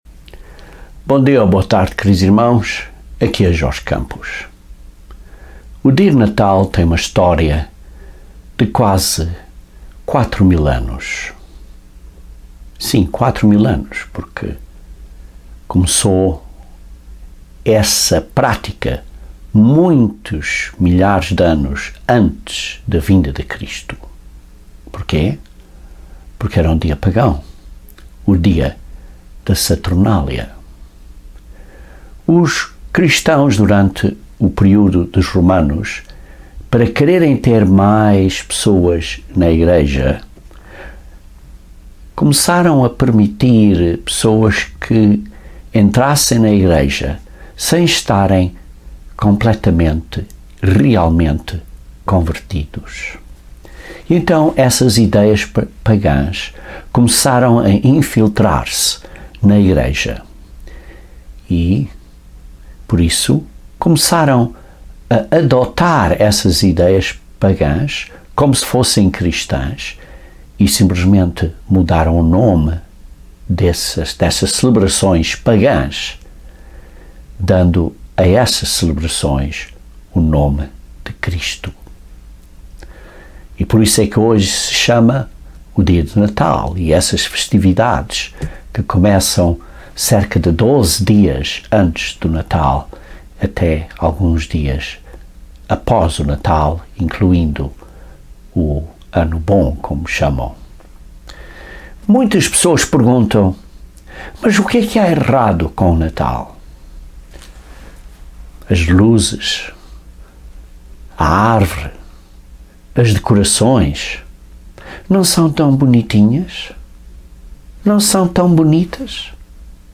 Este sermão analisa o que as celebrações do dia de Natal escodem acerca do significado vitalíssimo do nascimento de Cristo.